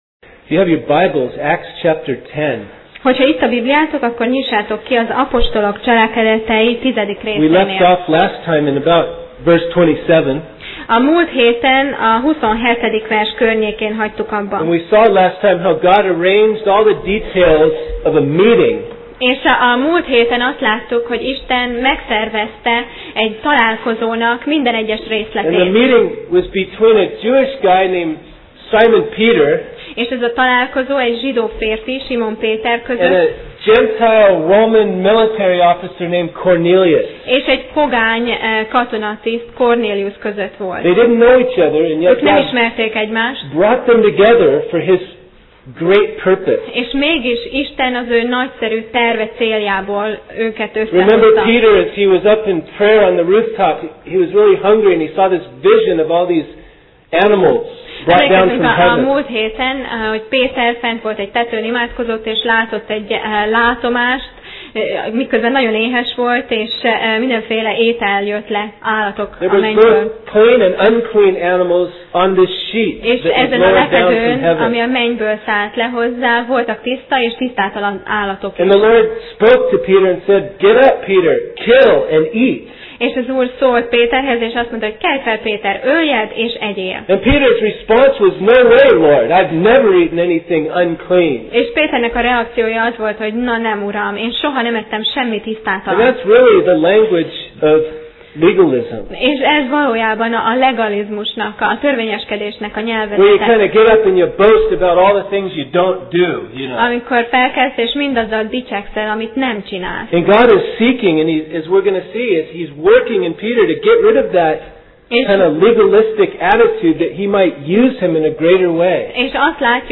Apostolok cselekedetei Passage: Apcsel (Acts) 10:28-48 Alkalom: Vasárnap Reggel